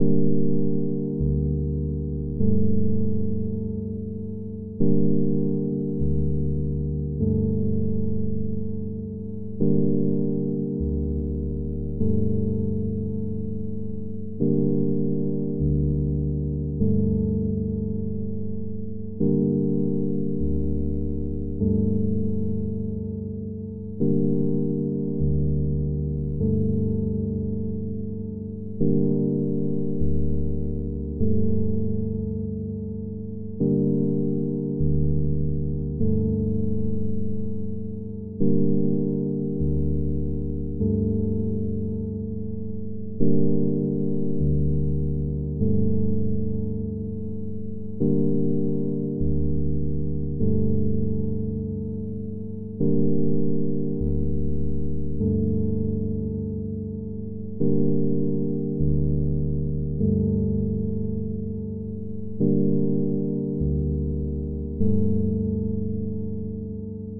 标签： 音乐 声景 环境 黑暗 低音 电钢琴
声道立体声